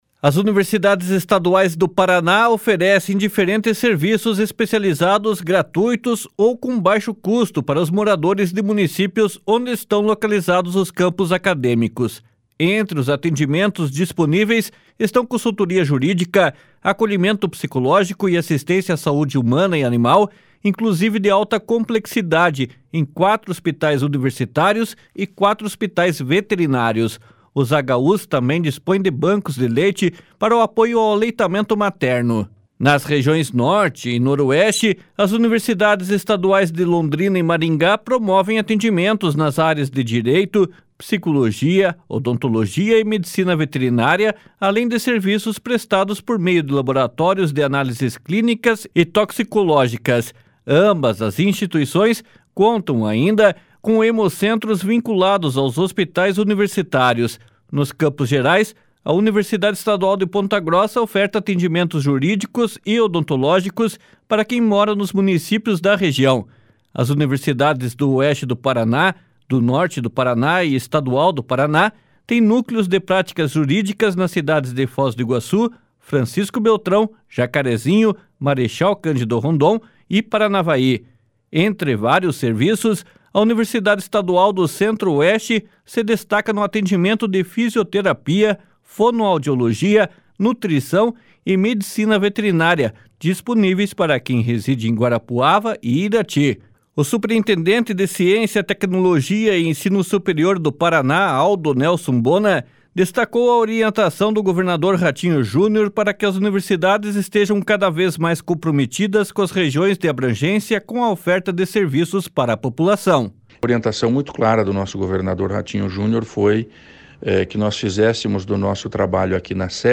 //SONORA ALDO NELSON BONA//